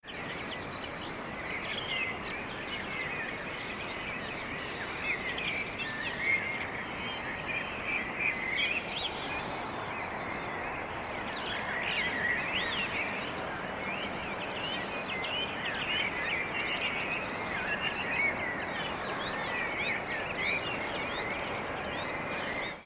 Bei Slunj / Rastoke finden wir schließlich einen Stellplatz, …
… und werden von einem tollen morgendlichen Vogelkonzert verwöhnt:
slunj-voegel-verstaerkt.mp3